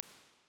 CiderSpatial_LIVE.wav